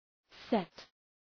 Προφορά
{set}